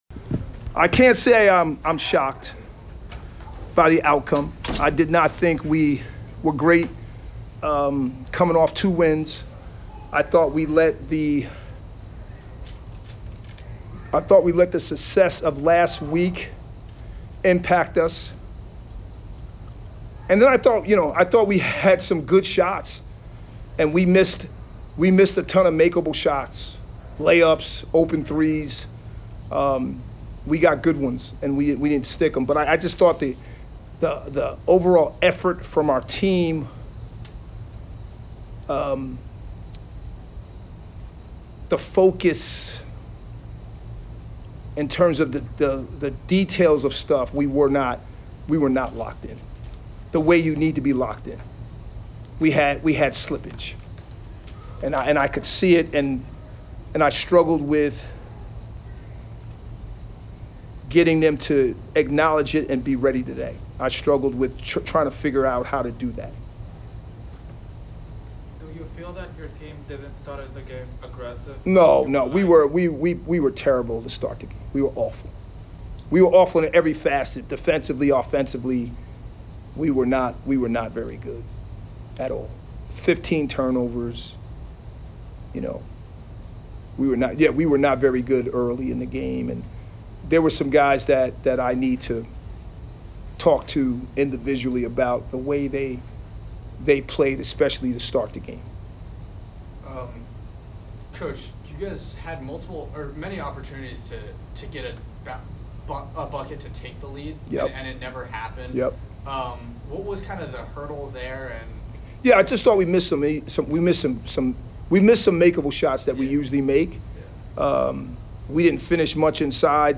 Sacred Heart Postgame Interview